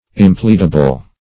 Search Result for " impleadable" : The Collaborative International Dictionary of English v.0.48: Impleadable \Im*plead"a*ble\, a. Not admitting excuse, evasion, or plea; rigorous.